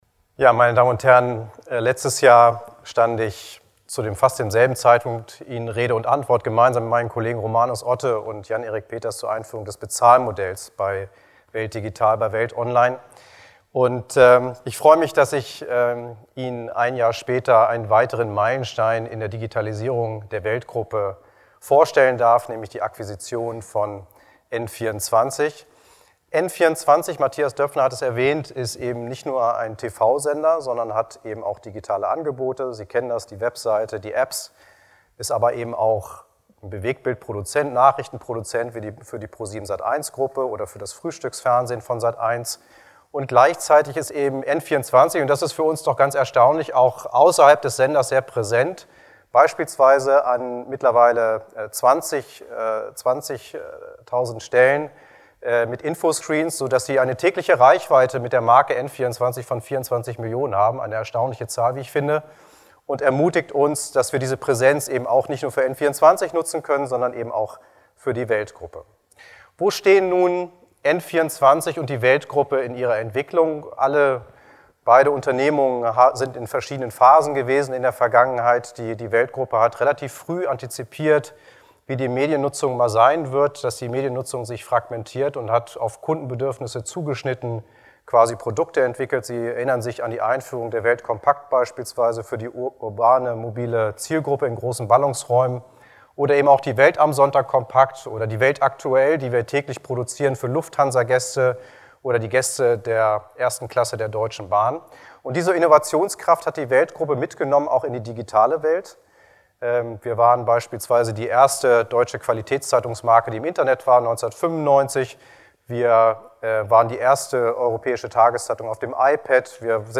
Was: Pressepräsentation zur Übernahme von N24
Wo: Berlin, Axel-Springer-Hochhaus, 19. Etage